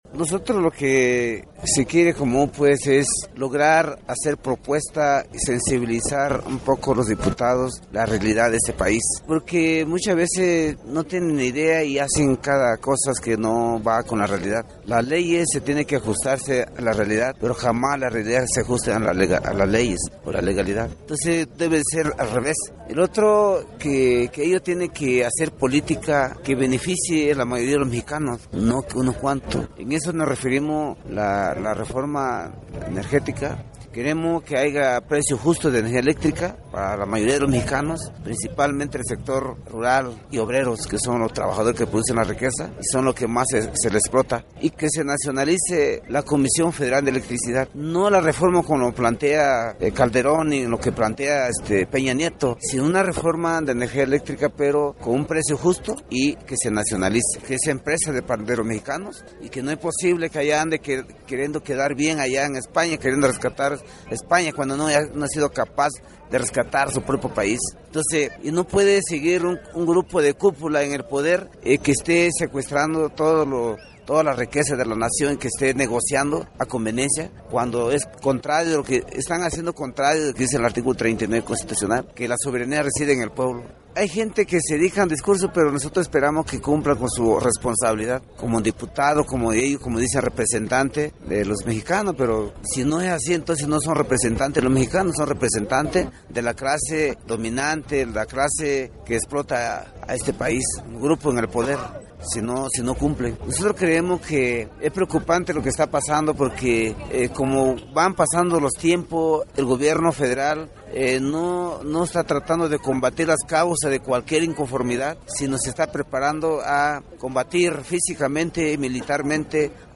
01 Entrevista